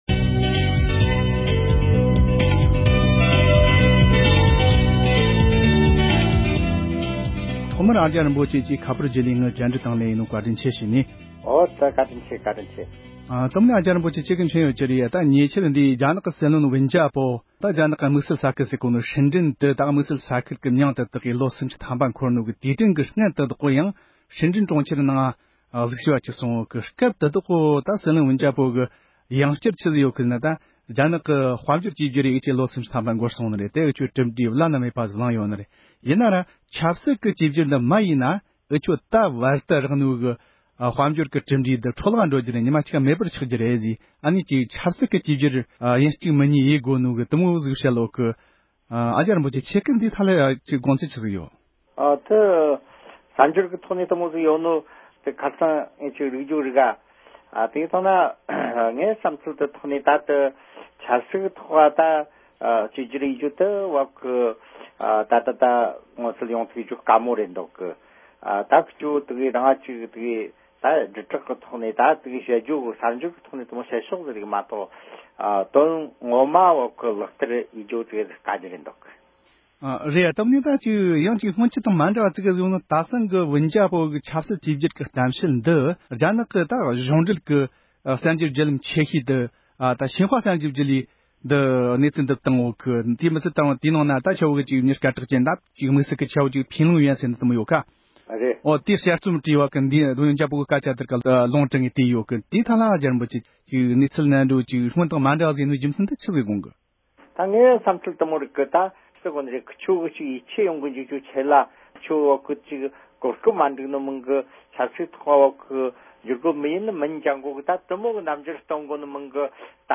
རྒྱ་ནག་གི་སྲིད་བློན་ལྦེན་ཇ་པོ་ཡི་ཆབ་སྲིད་བཅོས་བསྒྱུར་གཏམ་བཤད་ཐད་བགྲོ་གླེང༌།